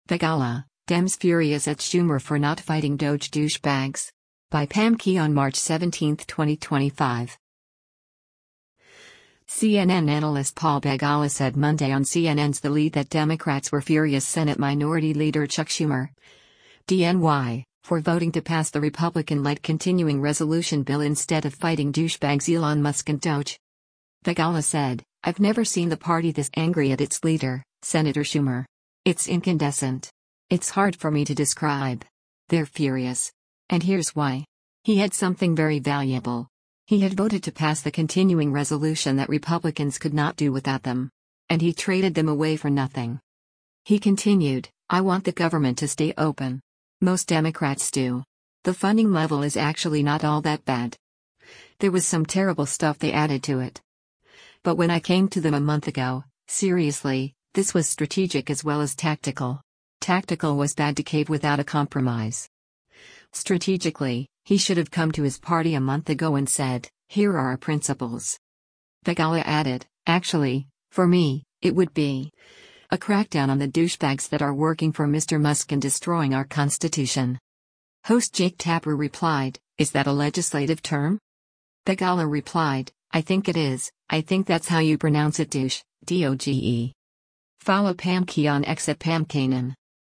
CNN analyst Paul Begala said Monday on CNN’s “The Lead” that Democrats were “furious” Senate Minority Leader Chuck Schumer (D-NY) for voting to pass the Republican-led continuing resolution bill instead of fighting “douchebags” Elon Musk and DOGE.
Host Jake Tapper replied, “Is that a legislative term?”